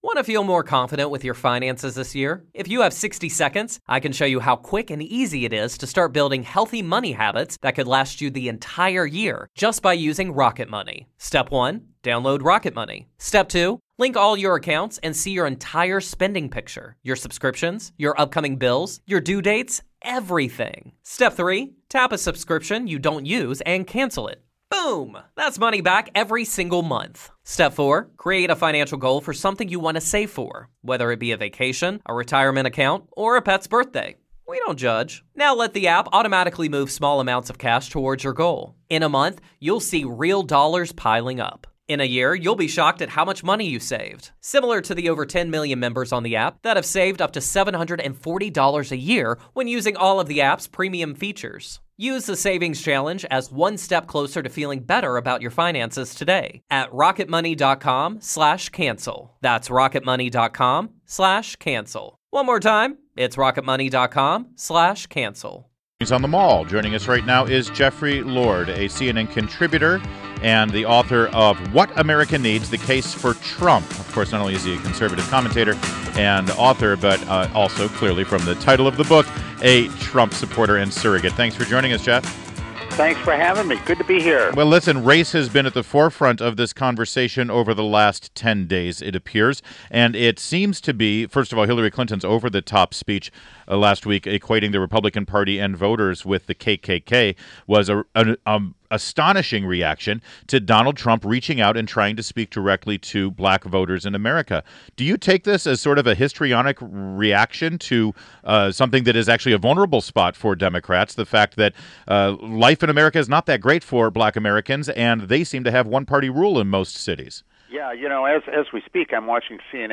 WMAL Interview - JEFFREY LORD - 08.29.16